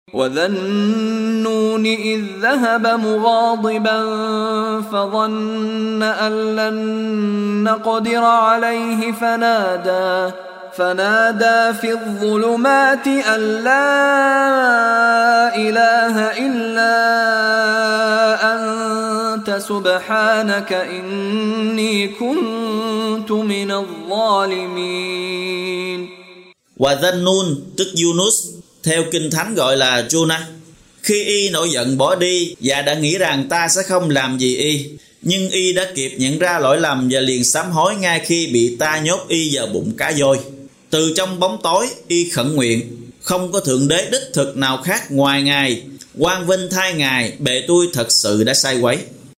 Đọc ý nghĩa nội dung chương Al-Ambiya bằng tiếng Việt có đính kèm giọng xướng đọc Qur’an